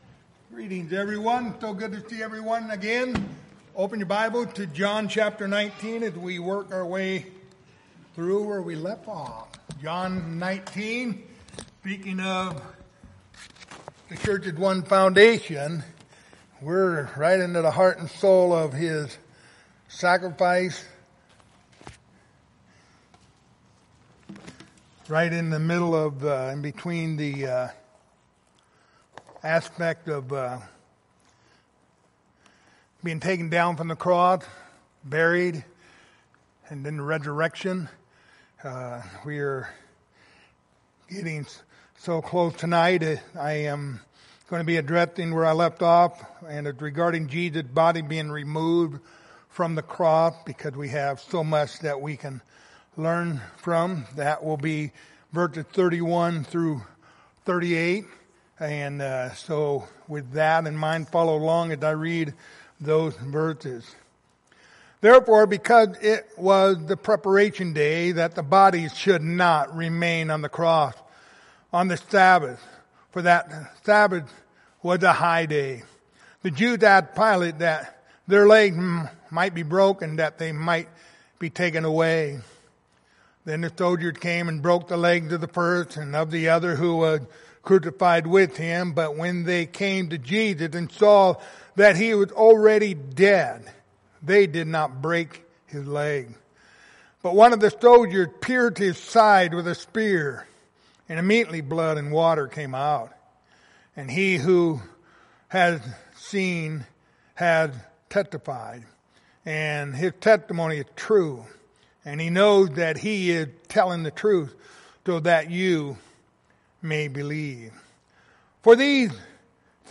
Passage: John 19:31-38 Service Type: Wednesday Evening